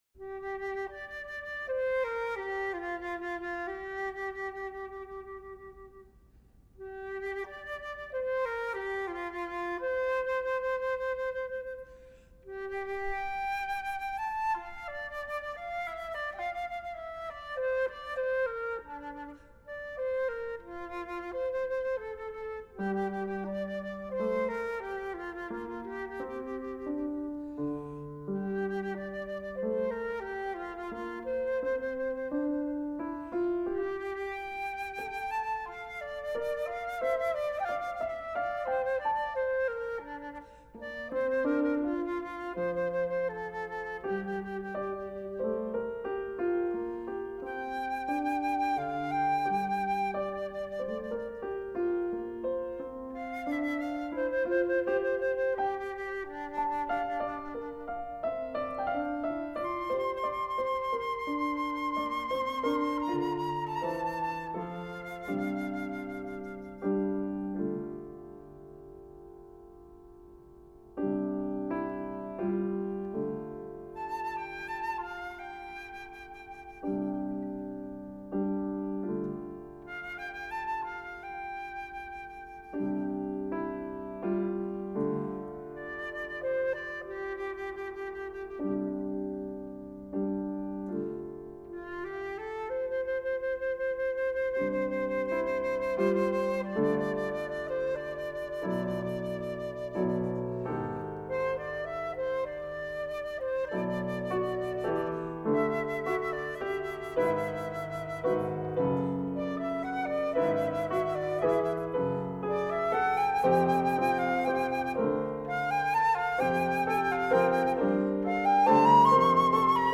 for Flute and Piano (1979)